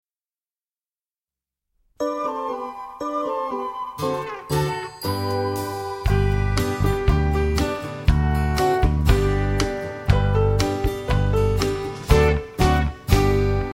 instrumental accompaniment music